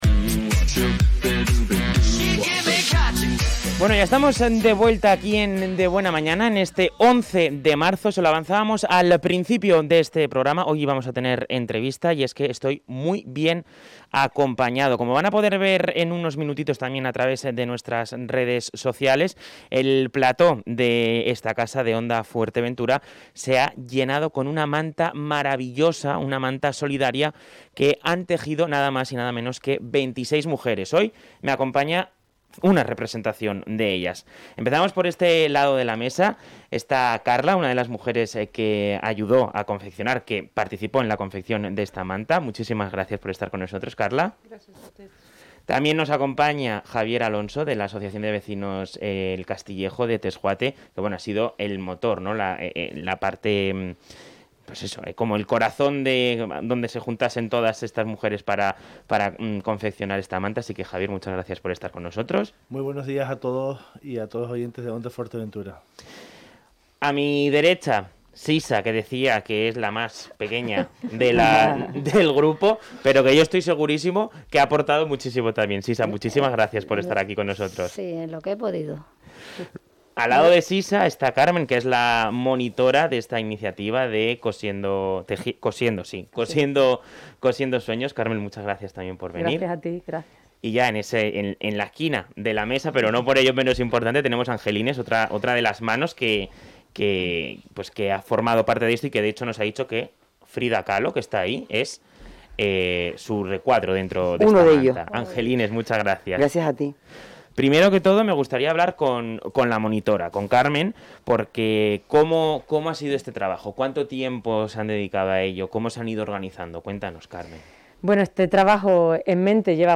La manta solidaria de Cosiendo Sueños de la AAVV El Castillejo de Tesjuate ha estado en Onda Fuerteventura y en el programa El Magacín, sus artífices, han contado como ha sido su confección.